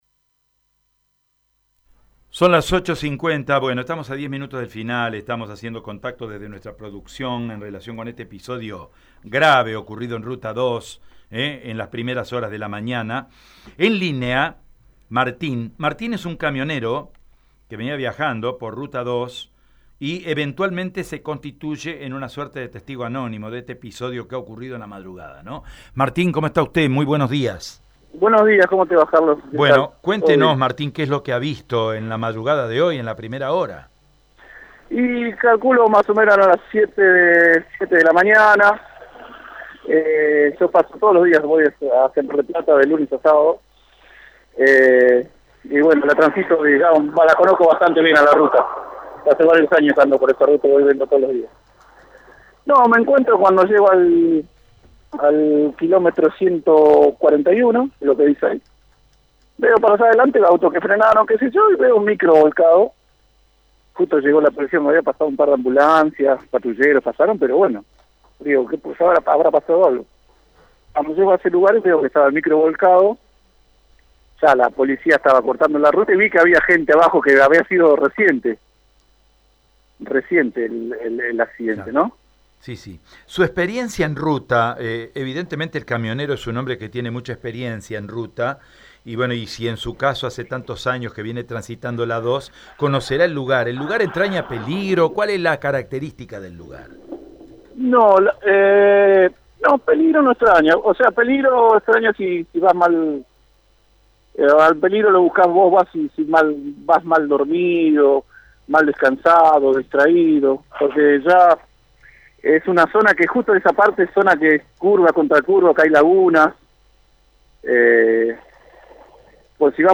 EM-CAMIONERO-TESTIGO-ACCIDENTE-RUTA-2.mp3